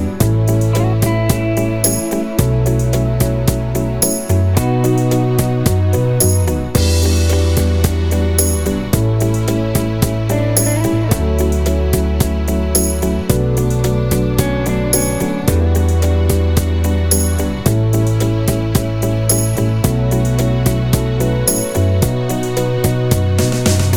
Minus Piano No Backing Vocals Soft Rock 4:08 Buy £1.50